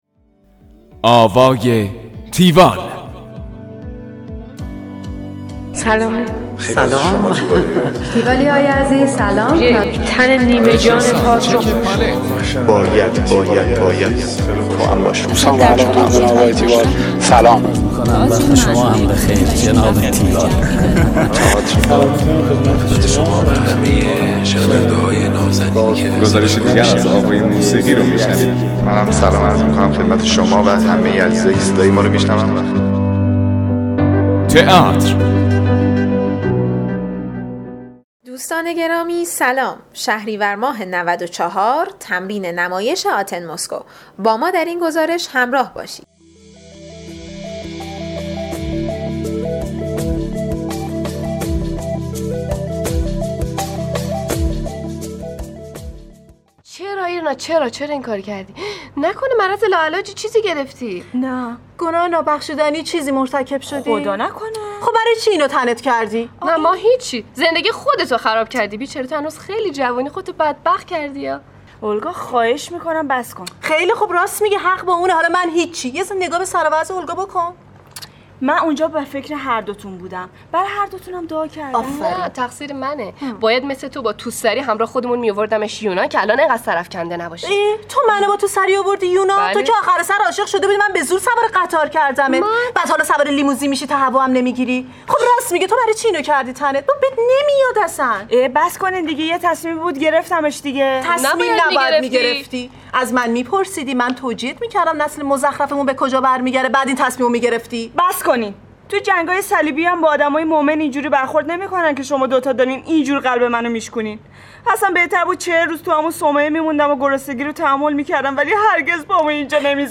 گزارش آوای تیوال از نمایش آتن موسکو
گفتگو با
همراه با بخش هایی از تمرین